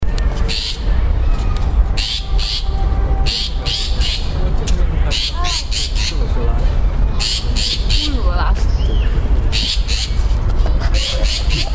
shrike-long-tailed003-Lanius-schach.mp3